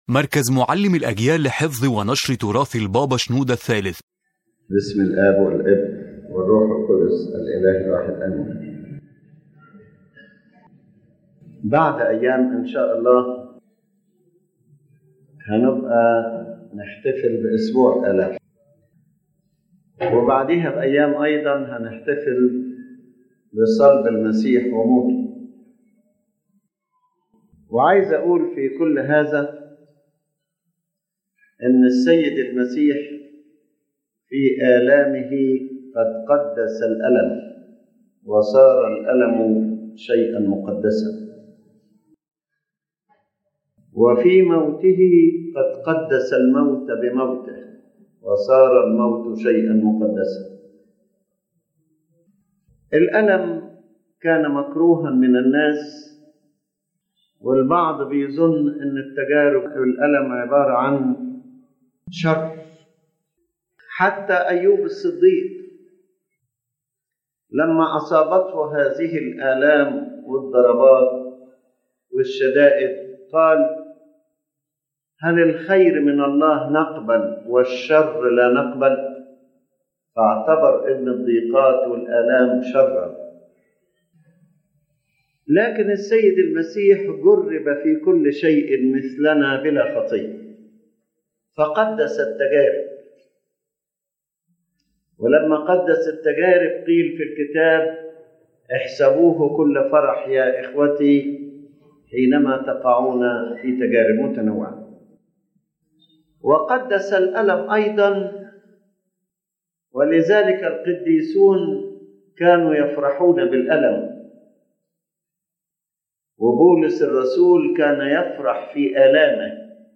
The lecture explains that the Lord Jesus sanctified all elements of human life by sharing in them: He sanctified suffering in His Passion, sanctified death by His death, sanctified the body and human nature and everything attached to it. The central idea is that Christ’s presence in every human experience transforms it into a means of holiness and salvation.